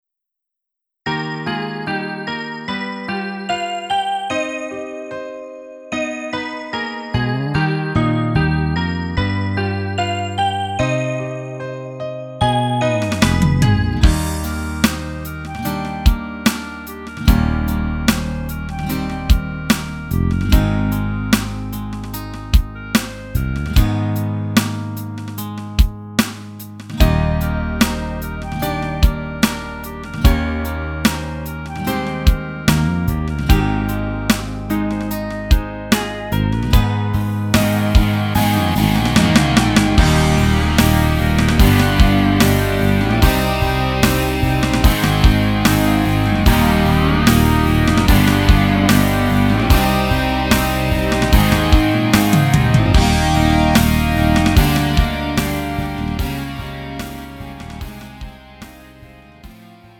음정 -1키 3:30
장르 가요 구분